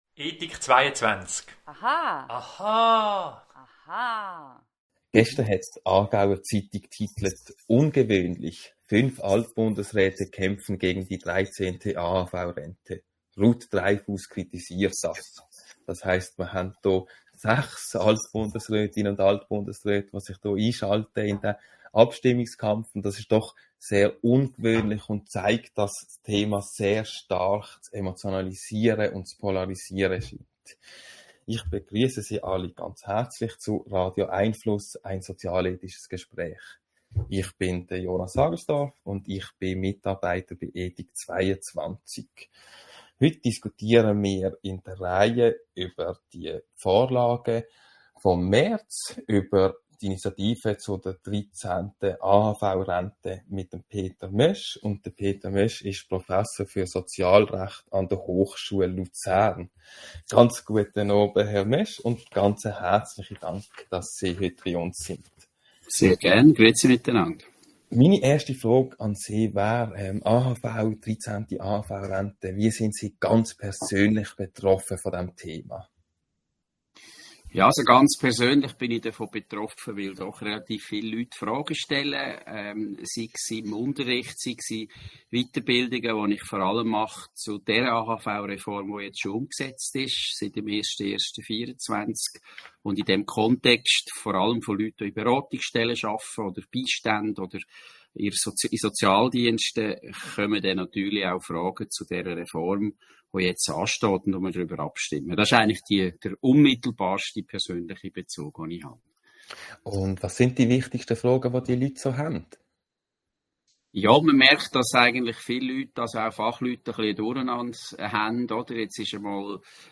Bleiben Sie über die kommenden Radio🎙einFluss Audio-Gespräche informiert!